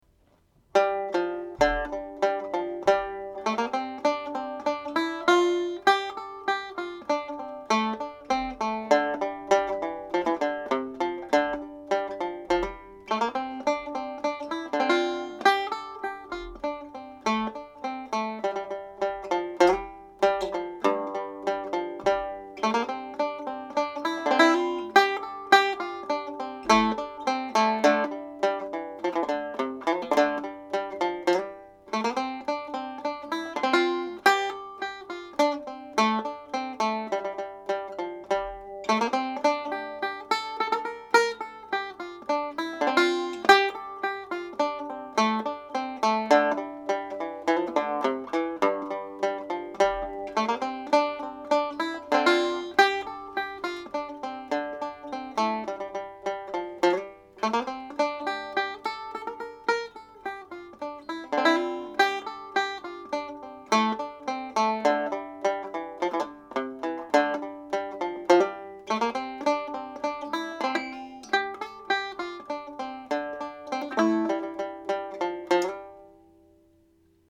This is a hornpipe in the key of G model which is sometimes played as a reel.
Caisleán An Óir played slowly with ornaments
golden-castle-slow-with-ornaments.mp3